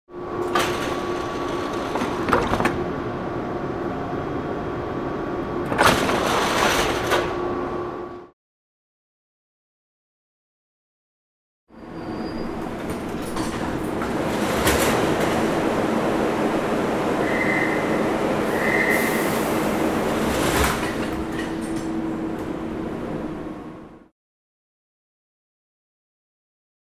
Метро, двери распахиваются, смыкаются